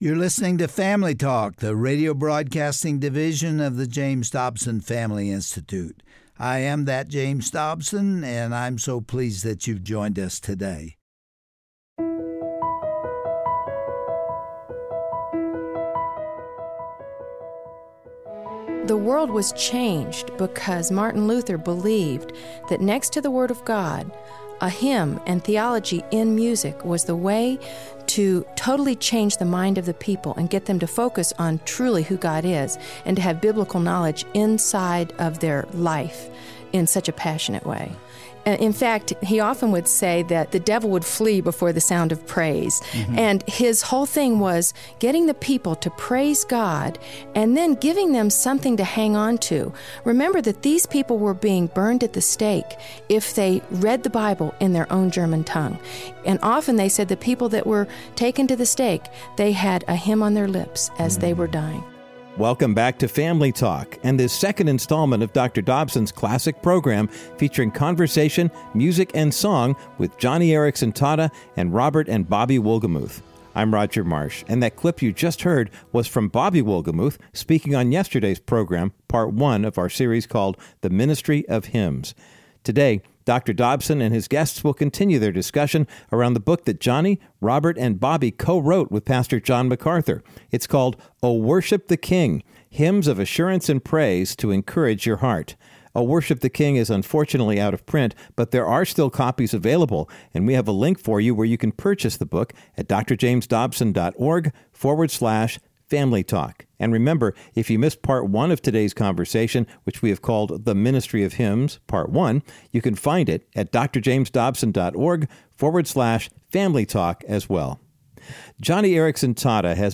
On today’s edition of Family Talk, she shares that she often awakes feeling burdened with the struggle. But when Joni reads her Bible and sings a hymn, she gains an eternal perspective.